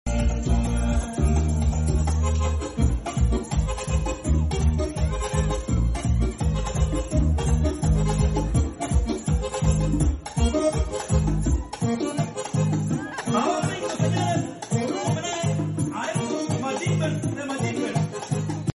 Car Audio